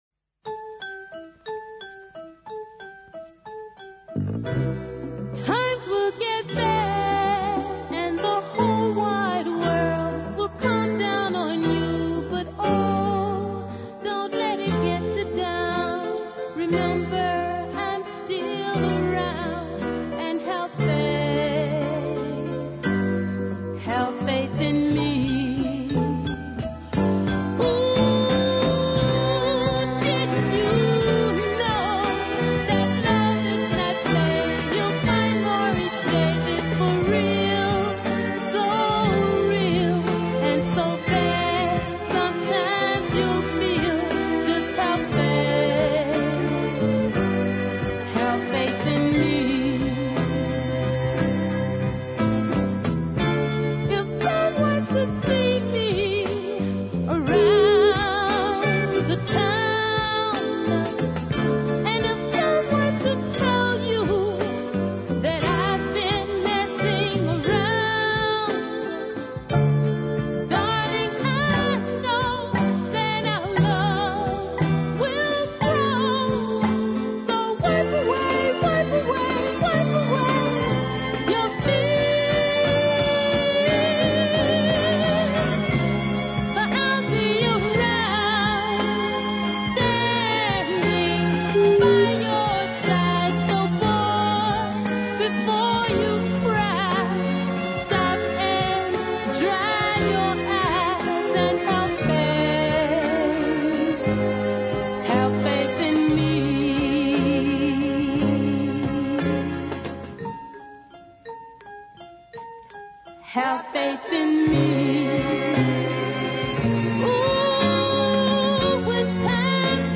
deeply soulful
soaring around the melody and sounding totally convincing.